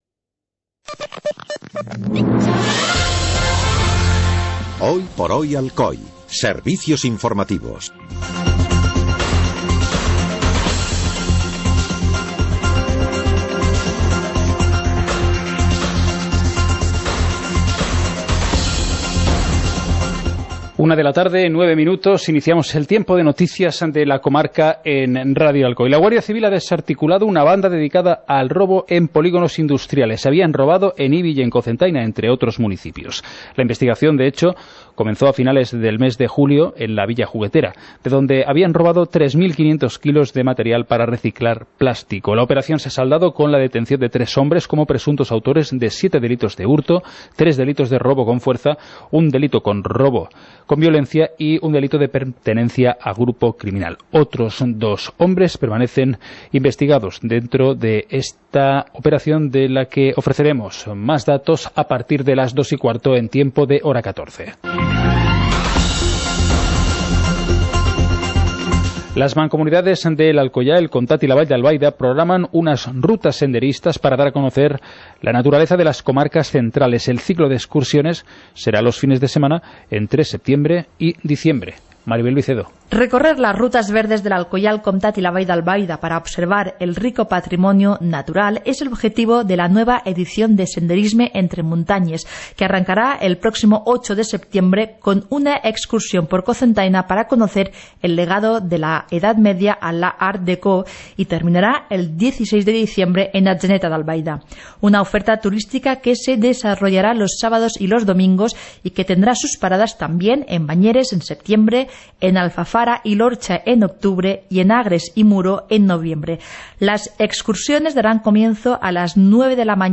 Informativo comarcal - viernes, 17 de agosto de 2018